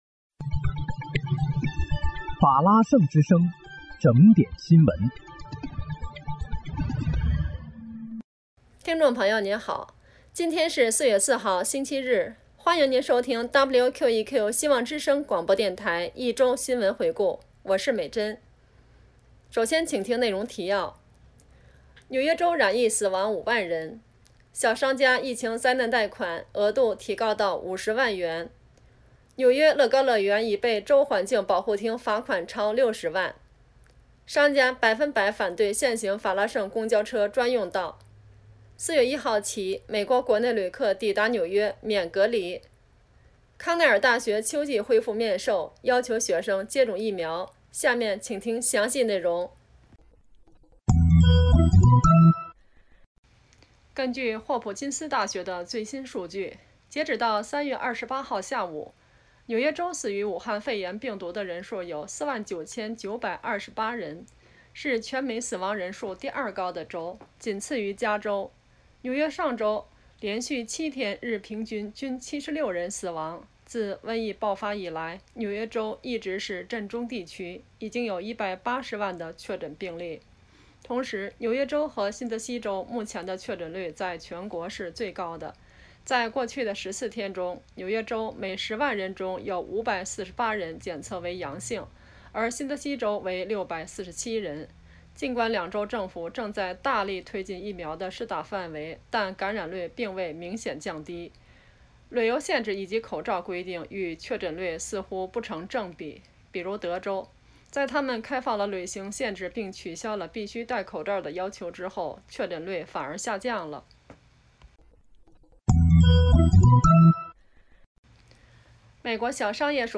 4月4日（星期日）一周新闻回顾